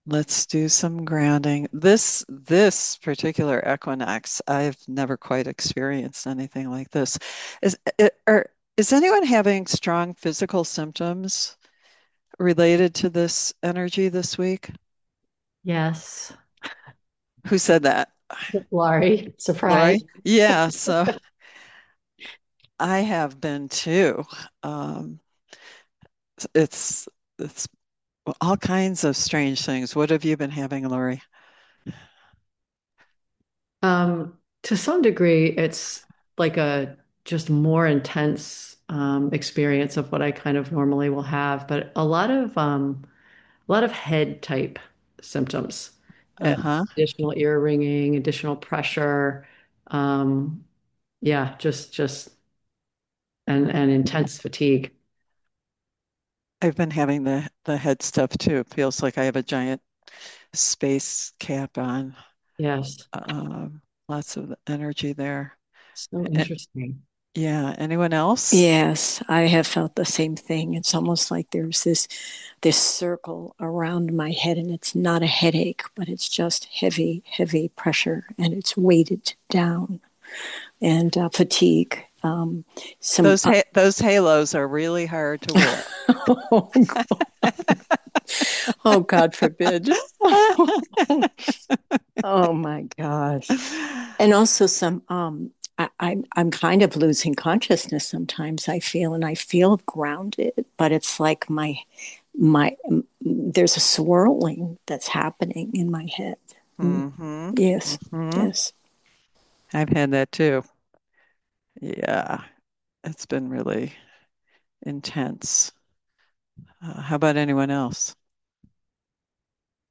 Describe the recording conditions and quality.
(Zoom gathering) 2026-Spring-Equinox-Ceremony.mp3